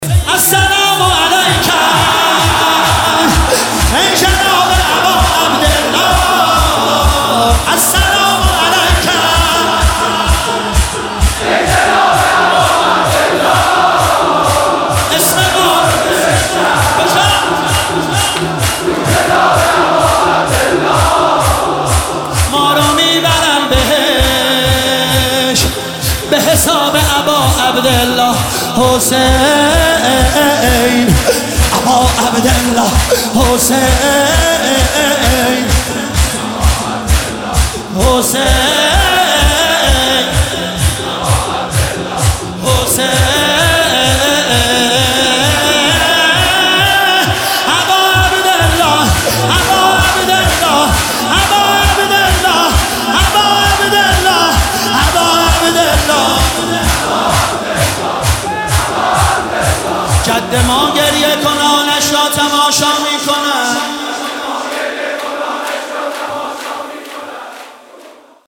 شهادت امام صادق (ع) 1404